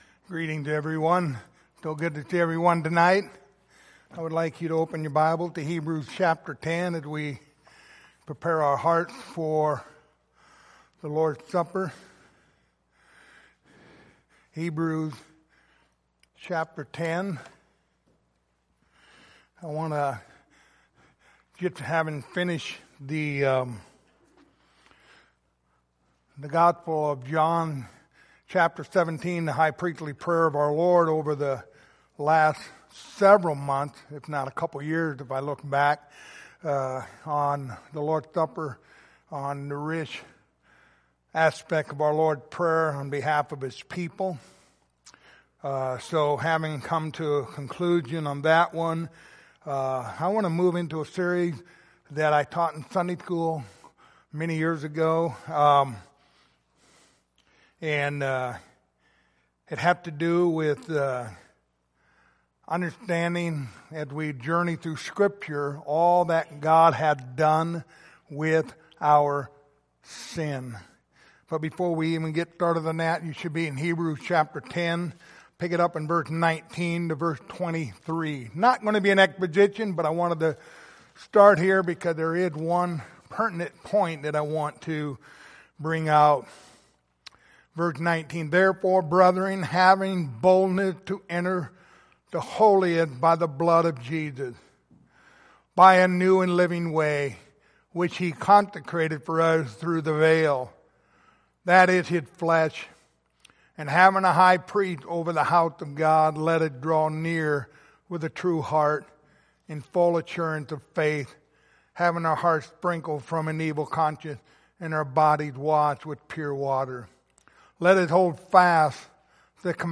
Passage: Hebrews 10:19-23 Service Type: Lord's Supper